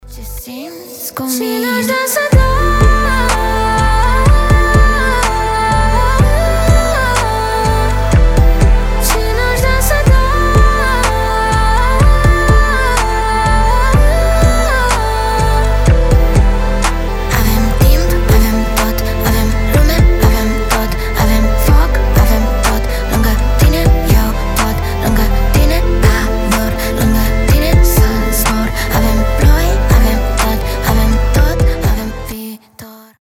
• Качество: 320, Stereo
мелодичные
красивый женский голос
румынские